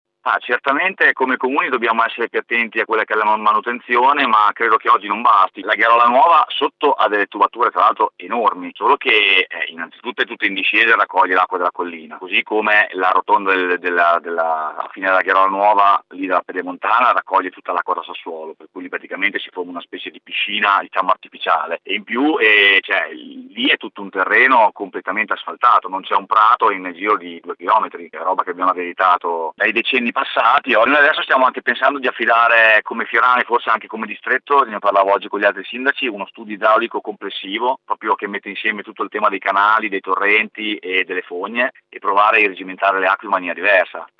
Sentiamo il sindaco di Fiorano, Massimo Biagini:
sindaco-fiorano.mp3